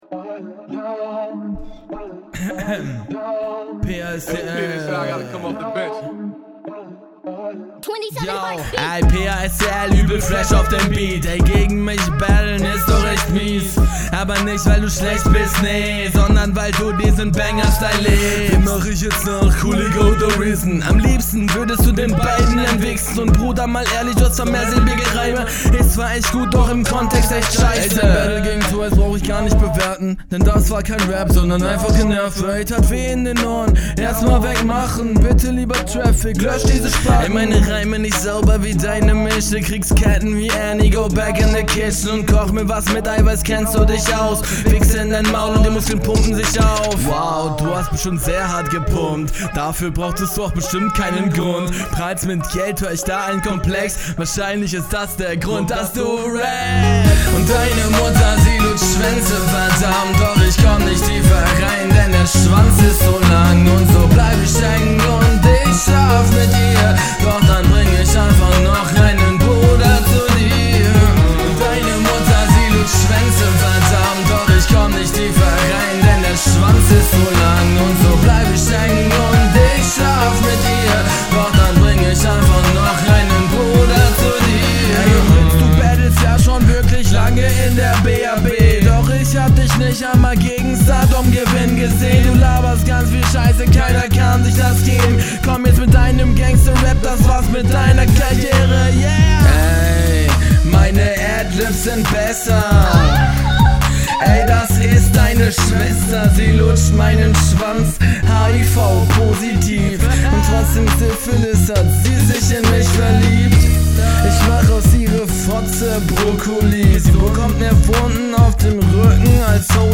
Soundtechnisch wirklich top (bis auf den Autotune-Part, dazu aber später mehr).